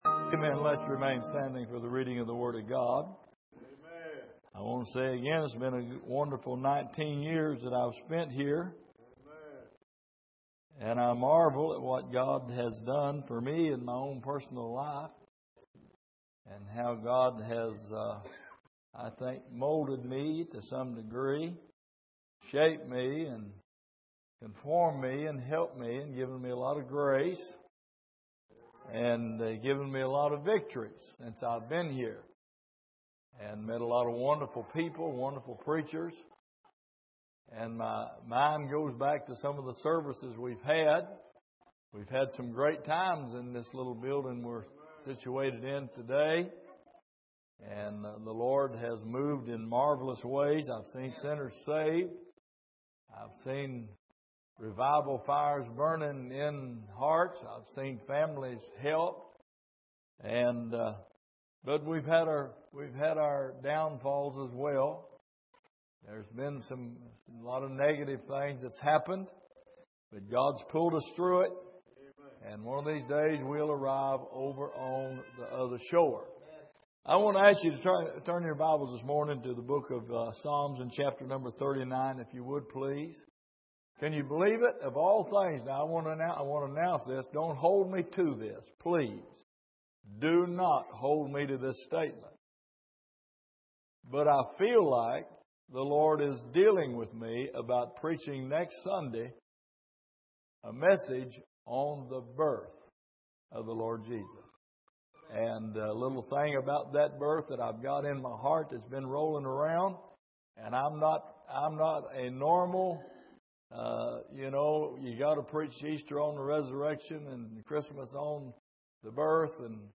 Passage: Psalm 39:1-13 Service: Sunday Morning